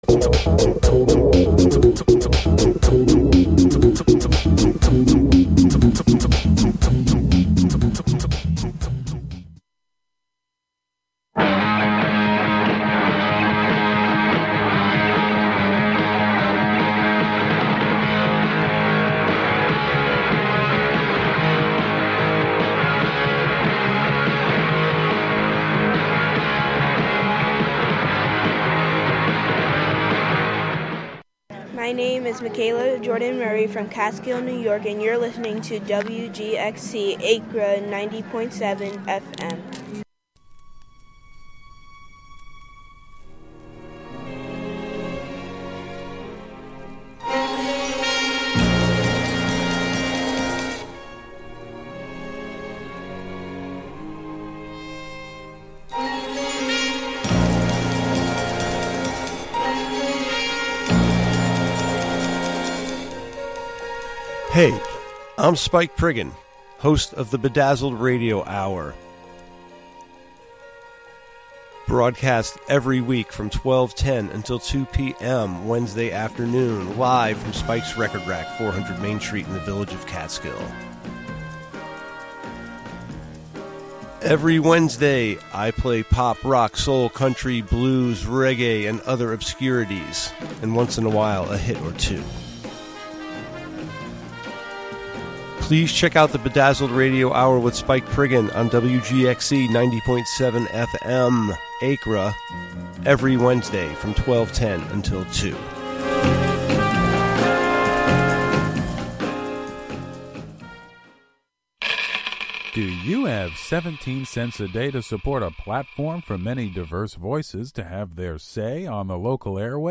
Tune in to this quarterly broadcast composed of local sound ecology, observational narration, and articulations of the mechanical components of the M49; its meanings and purpose reshaped as it traversed the machine of the road to arrive at its site in the forest of Wave Farm.